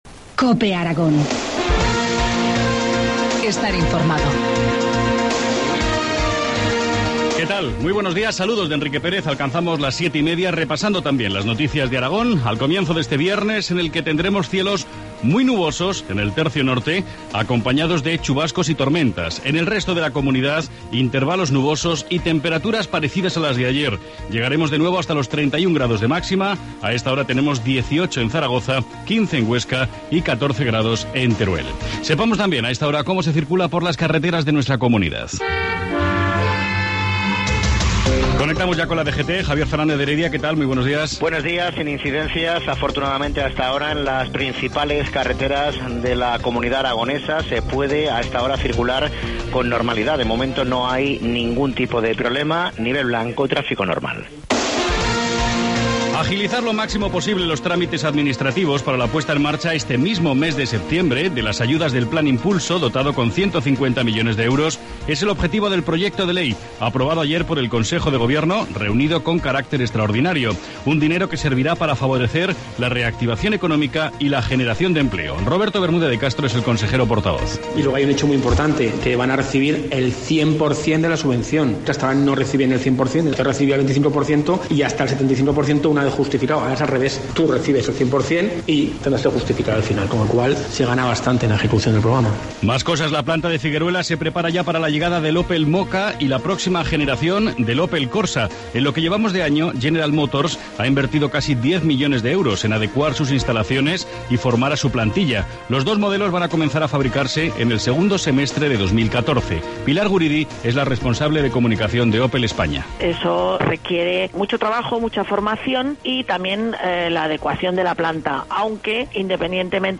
Informativo matinal, Viernes 6 septiembre, 2013, 7,25 horas